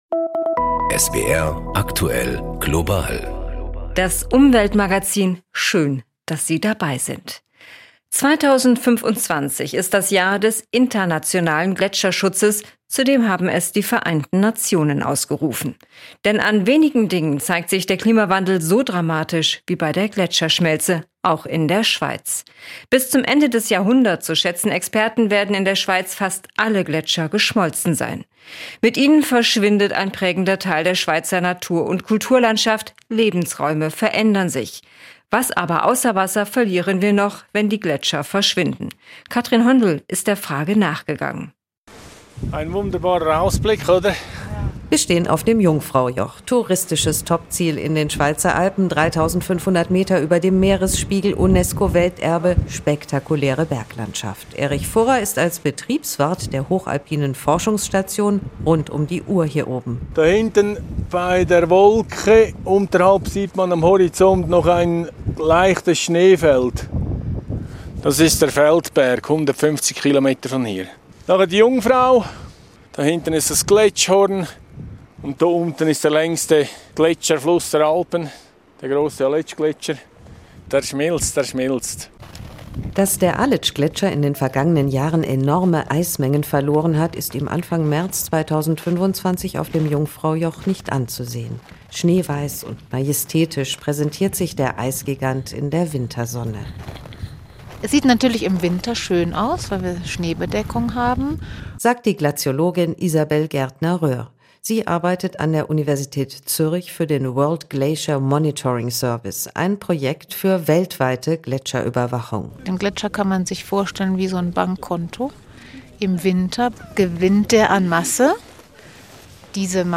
Was aber außer Wasser verlieren wir noch, wenn die Gletscher verschwinden? Ein Feature